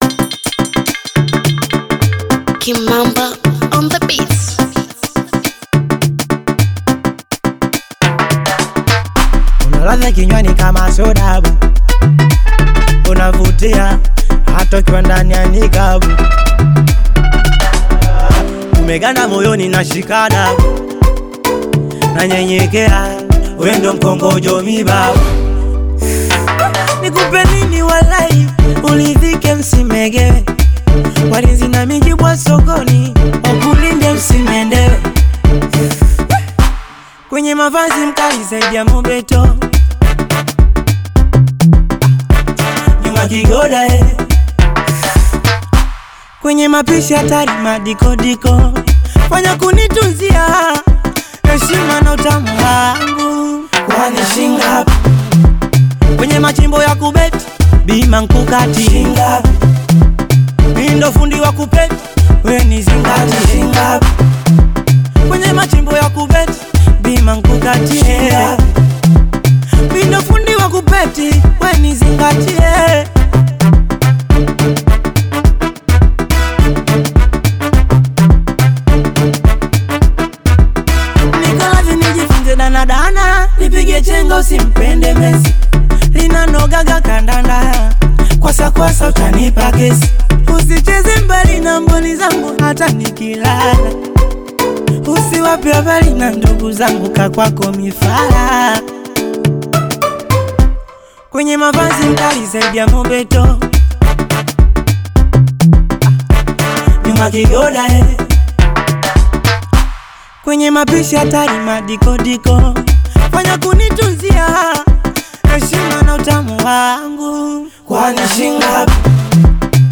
AudioBongo flava
Genre: Bongo Flava